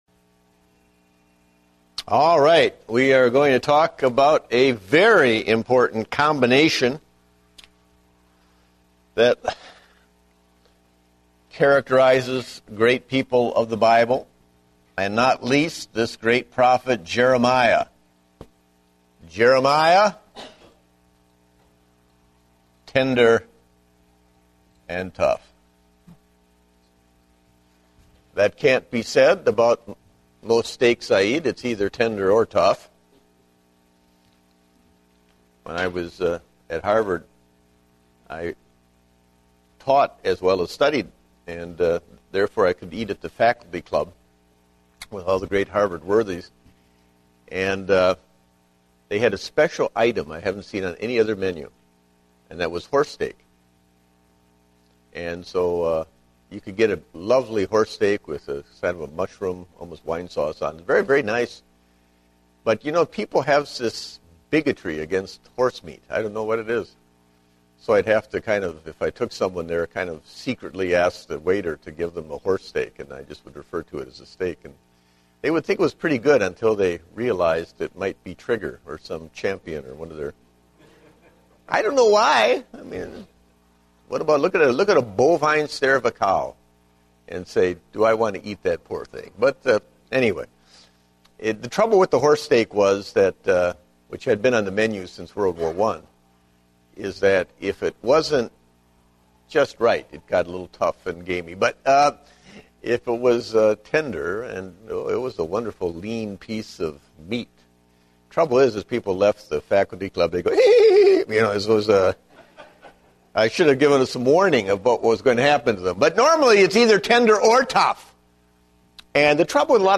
Date: April 11, 2010 (Adult Sunday School)